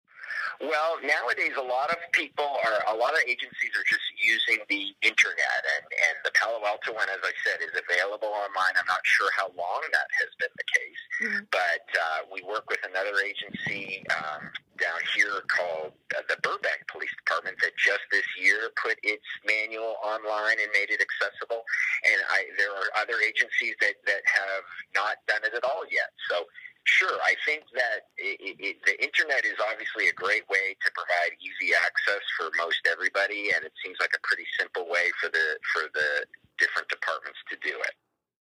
Through interviews